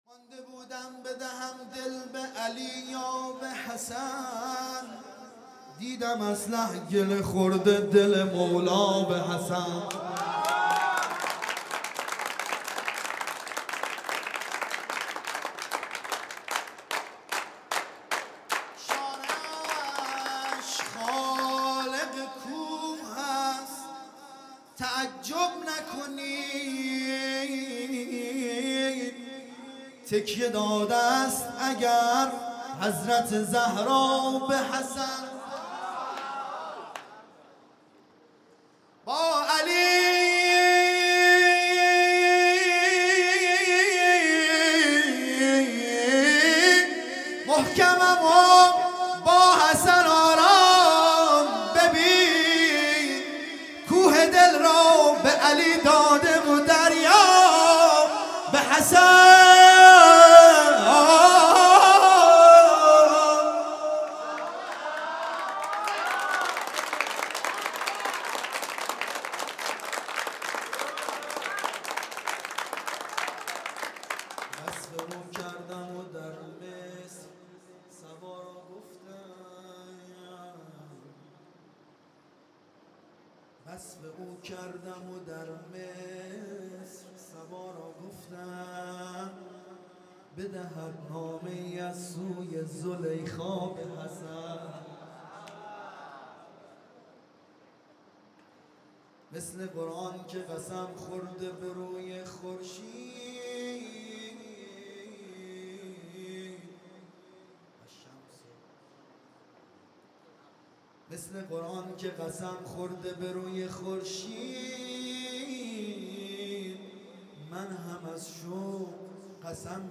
مدح